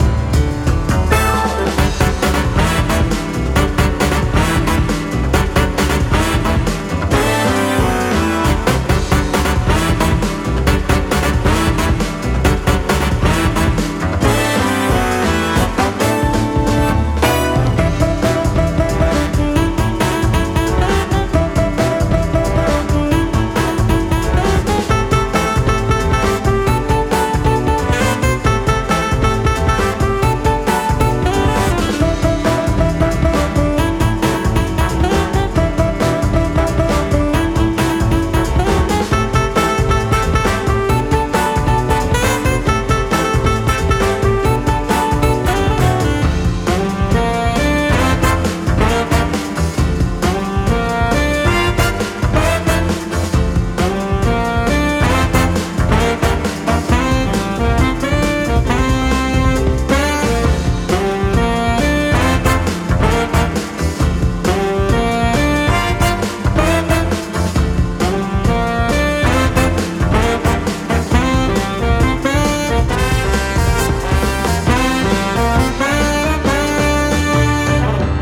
Music for battle theme.